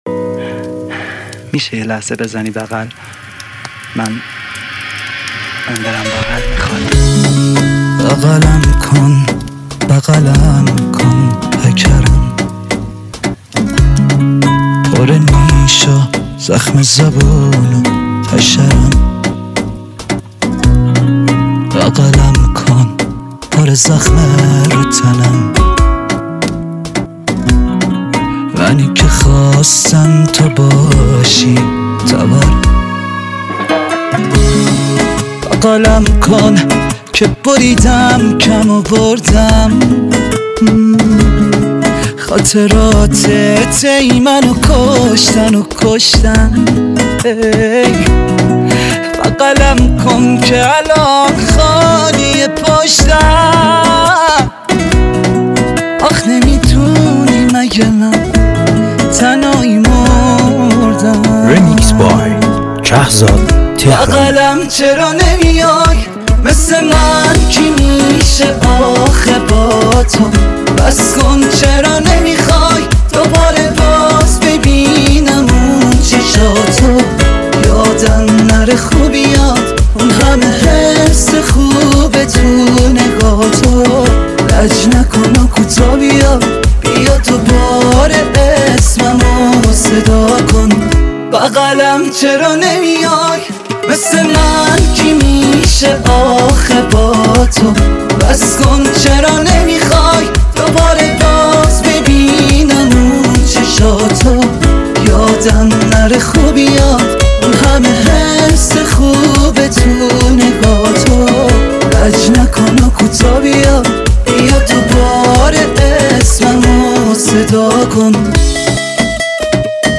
remix تند بیس دار
ریمیکس